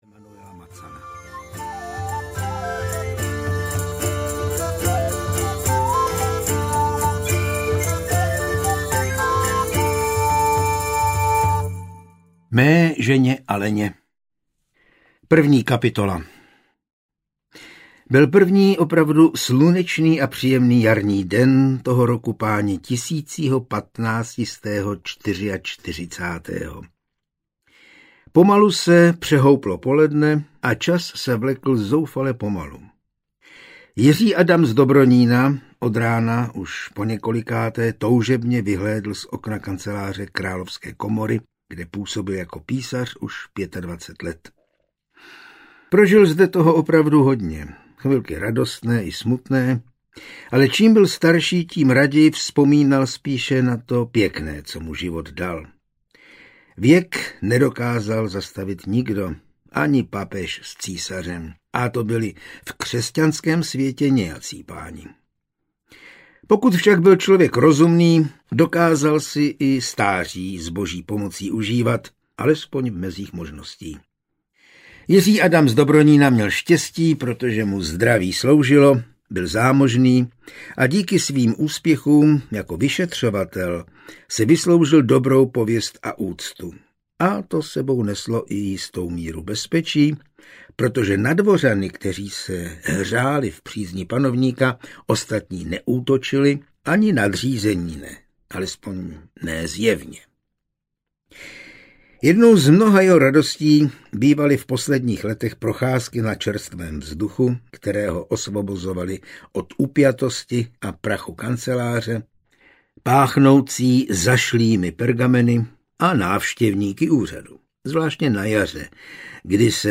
Mstitel z Jenštejna audiokniha
Ukázka z knihy